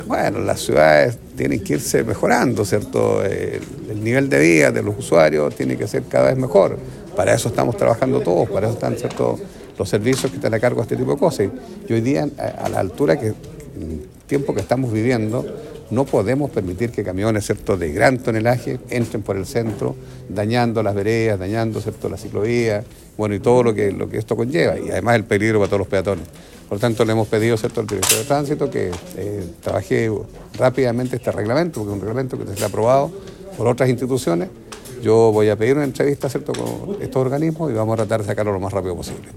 Durante la última sesión del Concejo Municipal de Osorno, se discutió la necesidad de trabajar en la creación de un reglamento que regule el tránsito de camiones de alto tonelaje por sectores residenciales y el centro de la comuna.
El alcalde de Osorno, Jaime Bertín, señaló que la normativa vigente sobre este tema data de la década de 1990, por lo que es urgente actualizarla para adaptarse a las nuevas realidades del tráfico y la infraestructura de la ciudad.